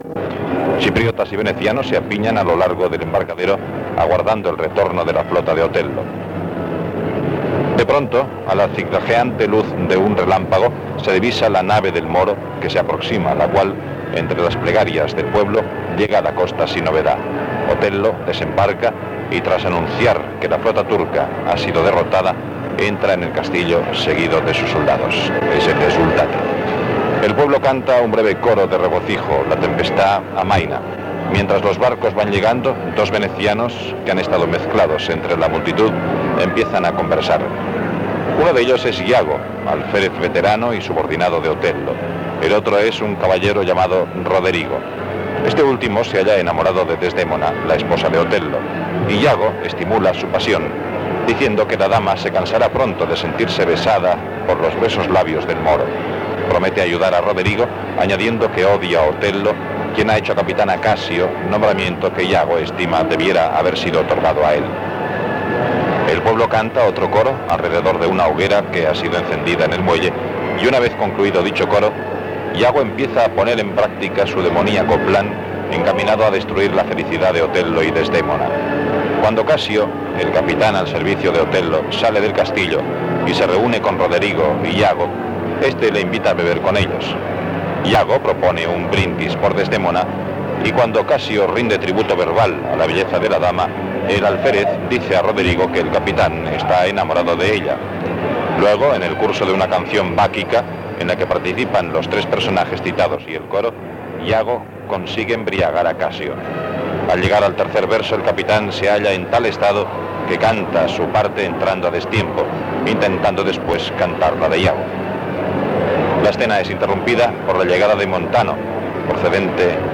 Transmissió de l'òpea Otello de Verdi des del Gran Teatre del Liceu de Barcelona.
Musical
Presentador/a
FM